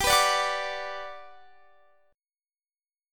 GM7 Chord
Listen to GM7 strummed